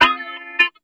07 FungShooey D#.wav